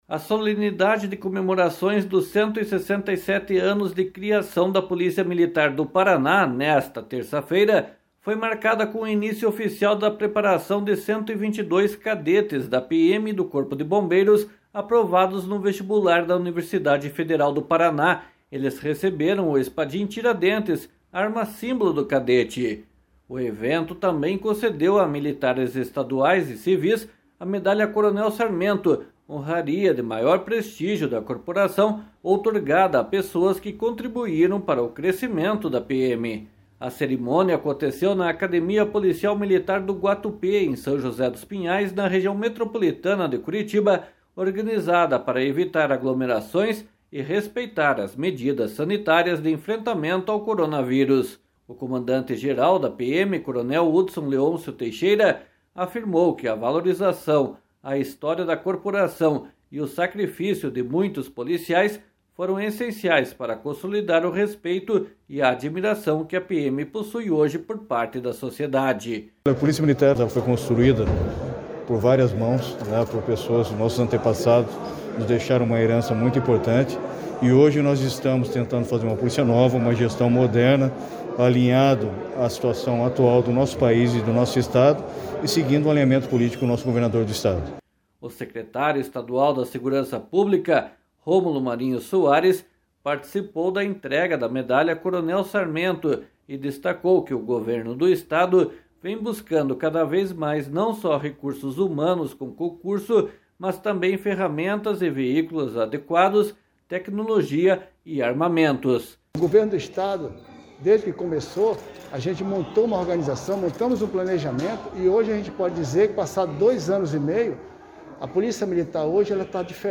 //SONORA HUDSON LEONCIO TEIXIERA//
//SONORA ROMULO MARINHO SOARES//